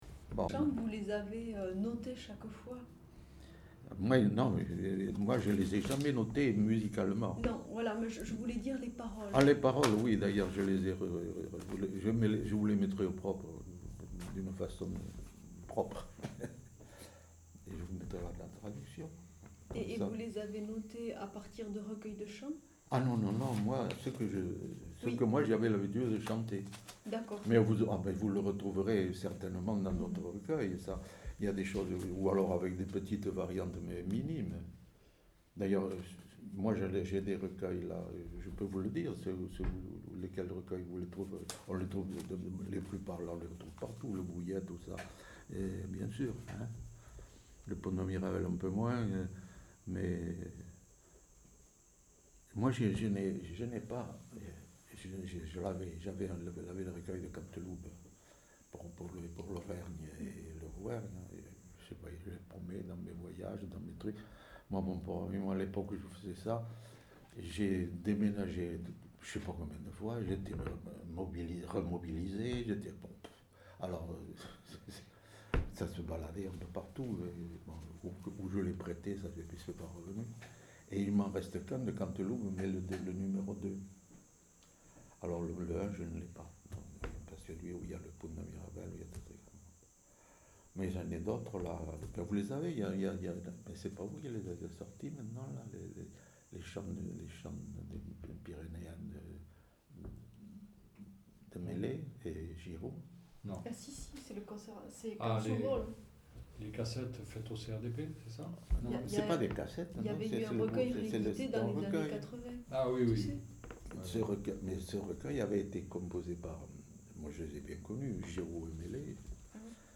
Aire culturelle : Rouergue
Genre : récit de vie